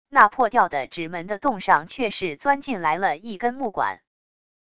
那破掉的纸门的洞上却是钻进来了一根木管wav音频生成系统WAV Audio Player